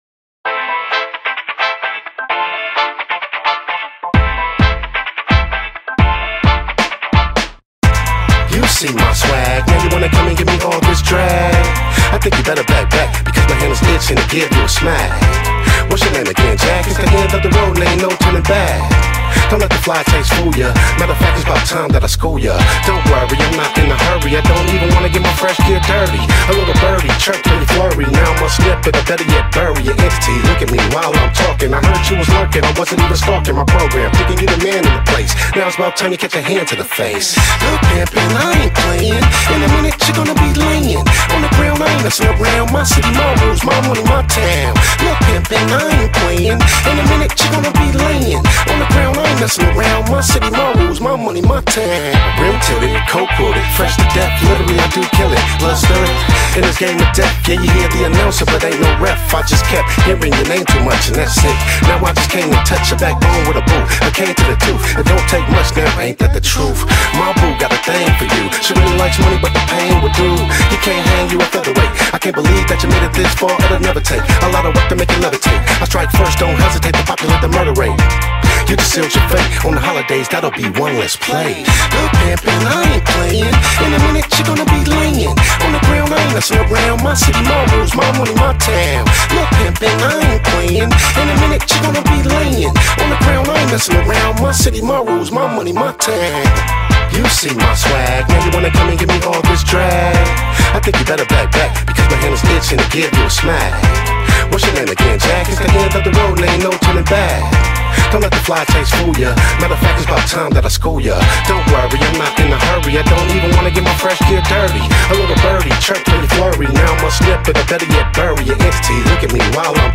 The battle theme